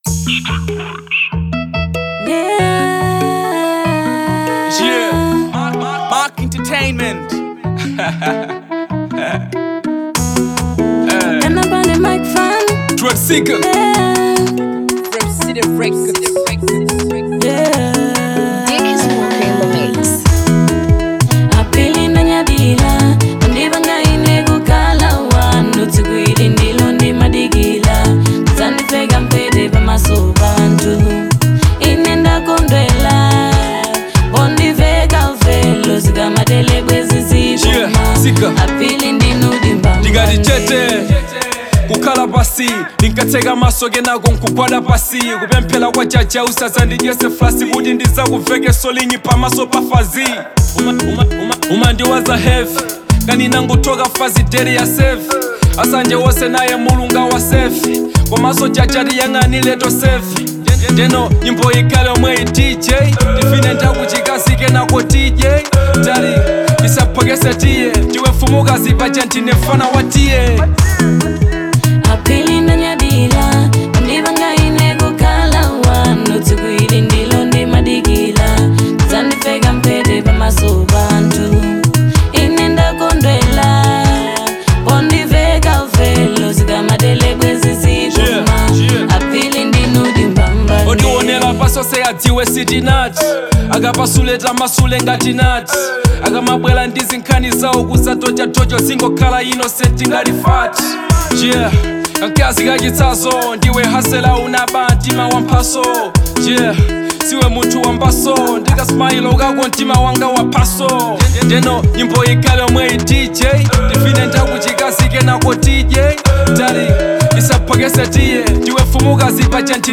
Fusion